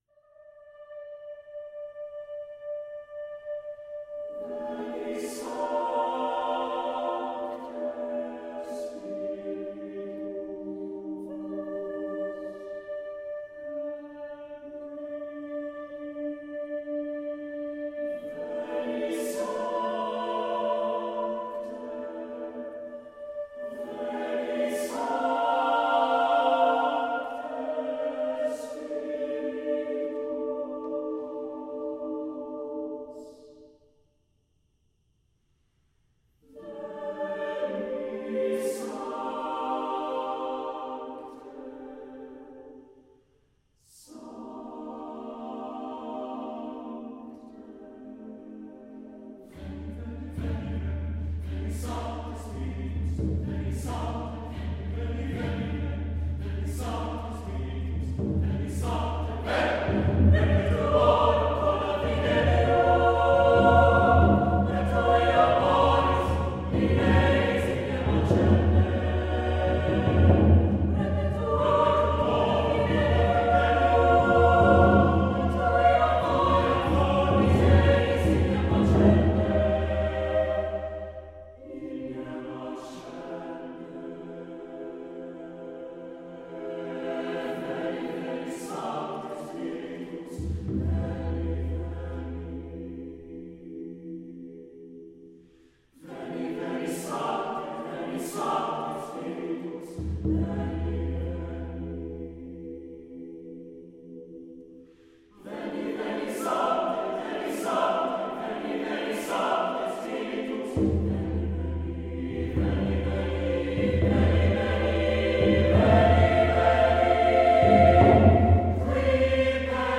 Voicing: SATB a cappella
Instrumentation: Buffalo Drum